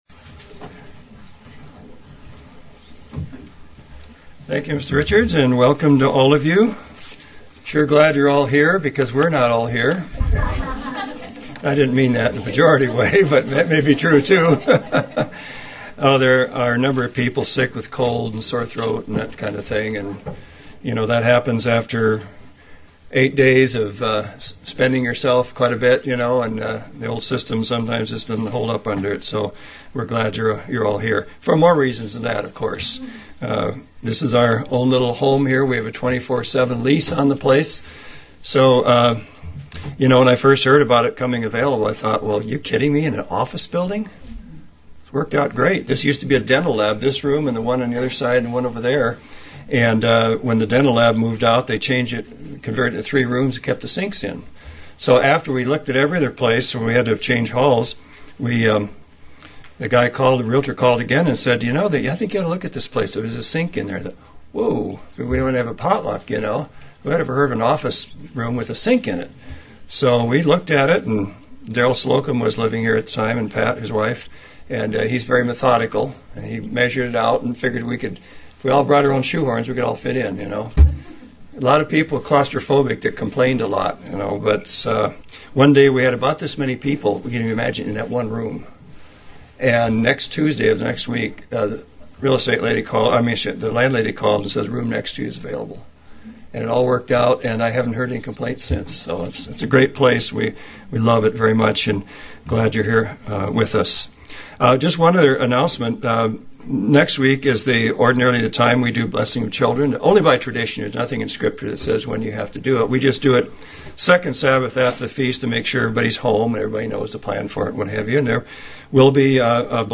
UCG Sermon Notes 10-2-10 How Are You Going to Spend the Off Season?